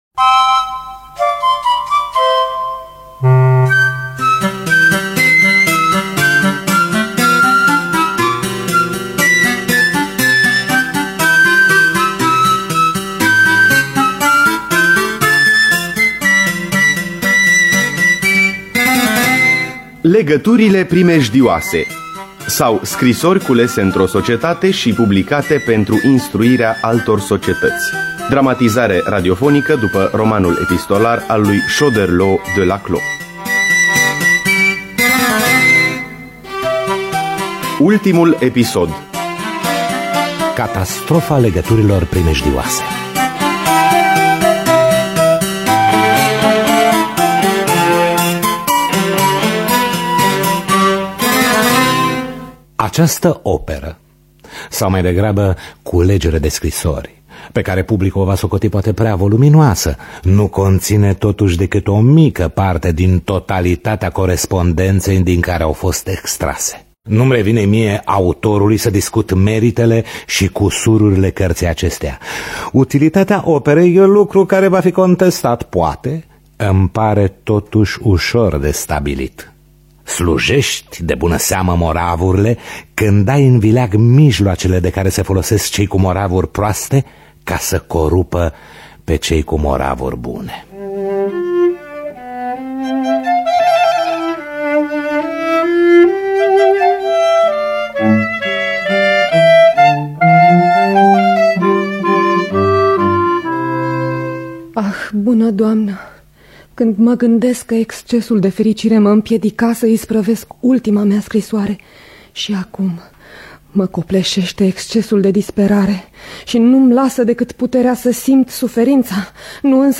Dramatizarea radiofonică
violoncel
flaut
vioară